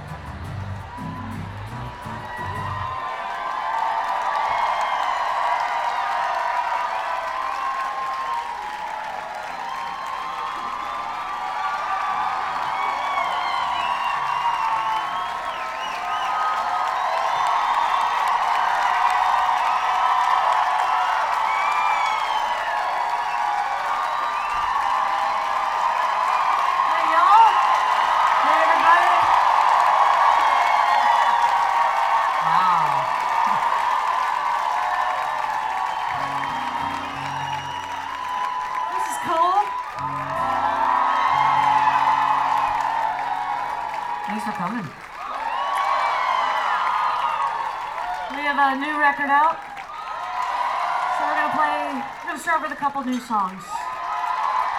01. talking with the crowd (0:54)